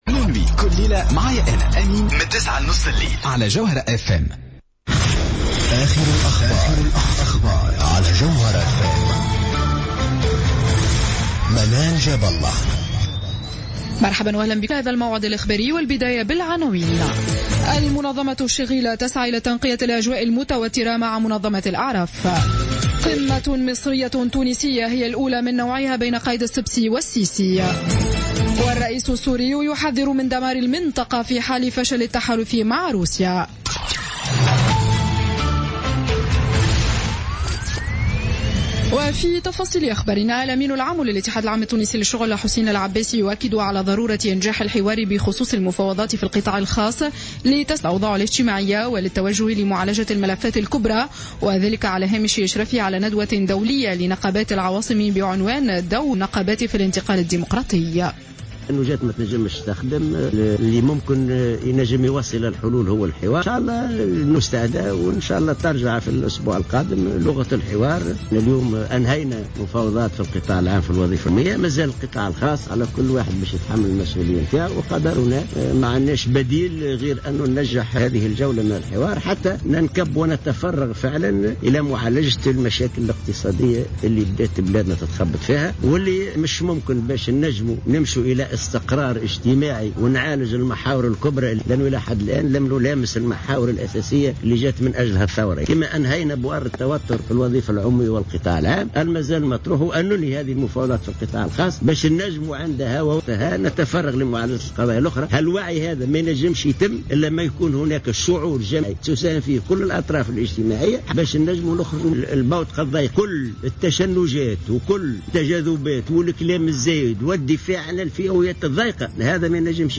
نشرة أخبار السابعة مساء ليوم الأحد 4 أكتوبر 2015